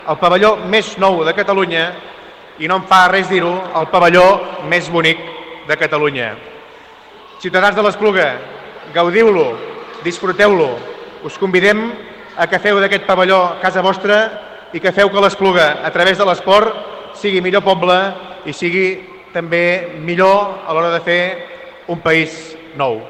ÀUDIO: El secretari general de l’Esport parla del «pavelló més nou» de Catalunya